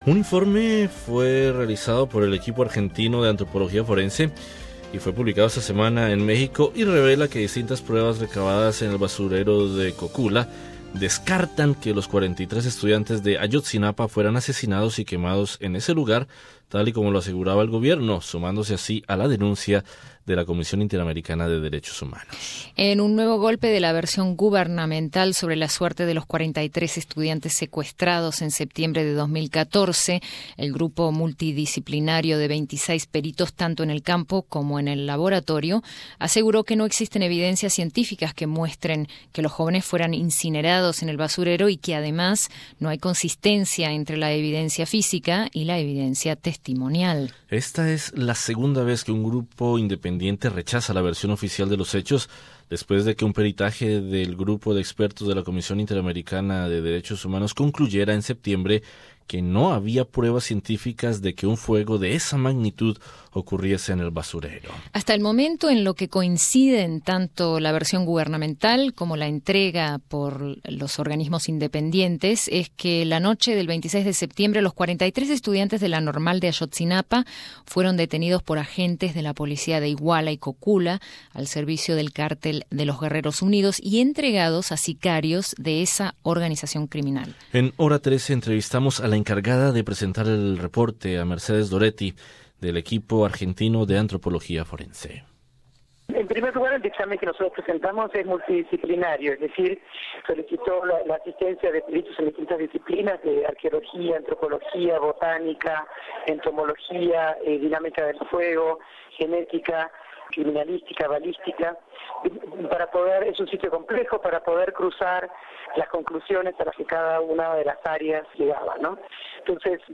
Un informe realizado por el Equipo Argentino de Antropología Forense y publicado esta semana en México, revela que distintas pruebas recabadas en el basurero de Cocula descartan que los 43 estudiantes de Ayotzinapa fueran asesinados y quemados en ese lugar, tal y como asegura el gobierno, sumándose así a la denuncia de la CIDH. Entrevista